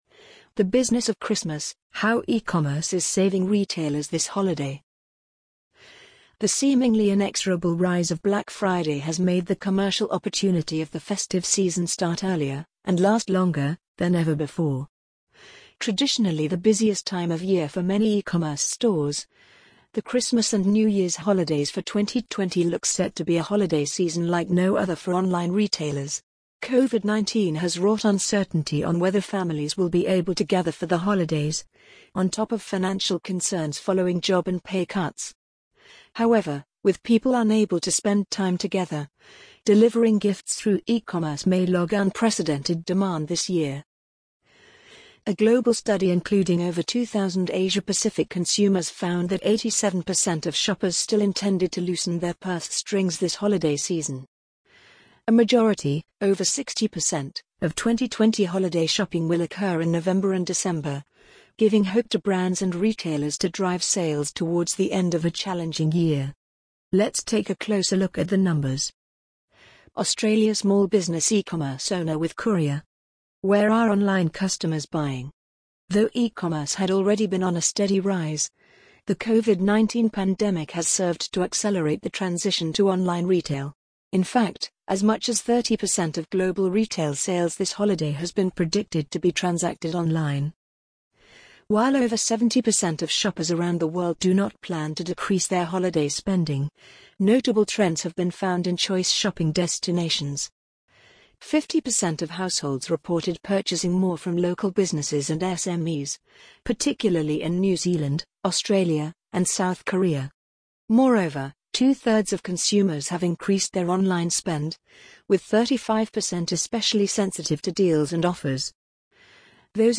amazon_polly_9372.mp3